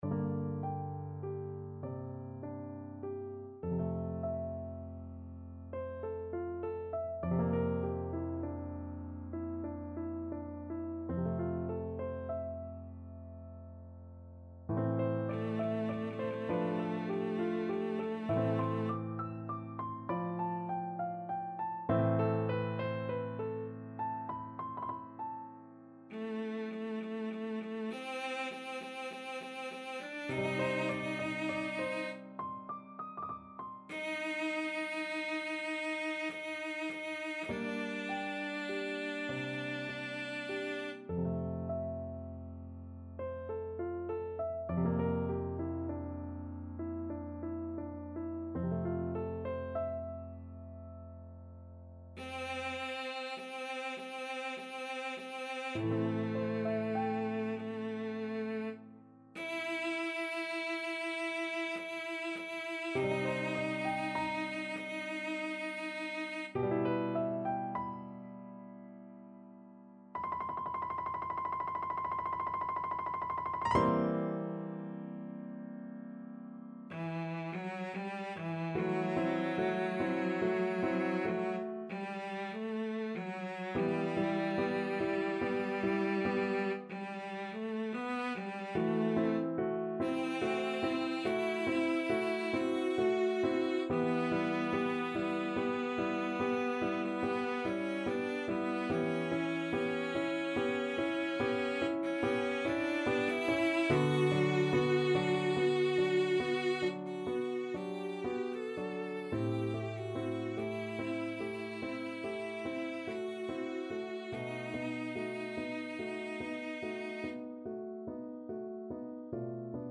Classical Cilea, Francesco E la solita storia del pastore from L'Arlesiana Cello version
6/8 (View more 6/8 Music)
C major (Sounding Pitch) (View more C major Music for Cello )
~ = 100 Lentamente =100
Cello  (View more Intermediate Cello Music)
Classical (View more Classical Cello Music)